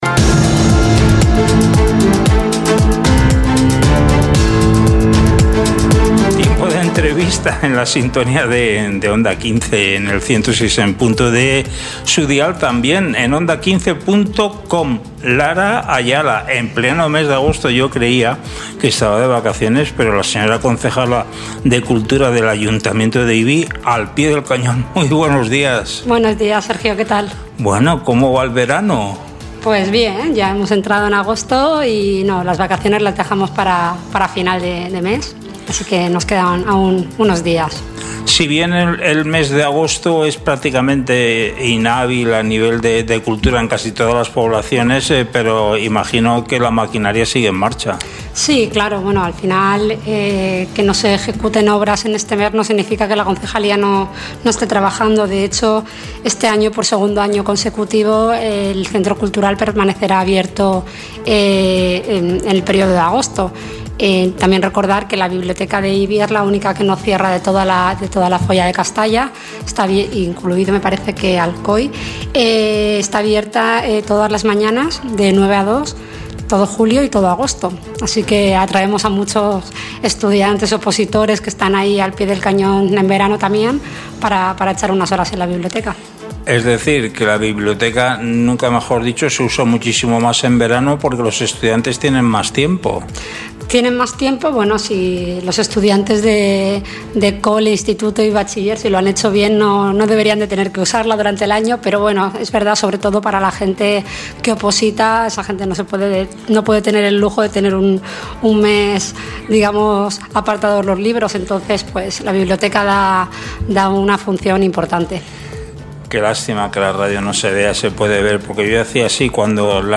Entrevista a Lara Ayala, concejala de cultura, bienestar animal, participación ciudadana y comunicación del Excmo. Ayuntamiento de Ibi - Onda 15 Castalla 106.0 FM
Hoy en nuestro Informativo, contamos con la presencia de Lara Ayala, concejala de Cultura, Bienestar Animal, Participación Ciudadana y Comunicación del Excmo. Ayuntamiento de Ibi.